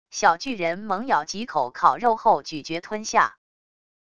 小巨人猛咬几口烤肉后咀嚼吞下wav音频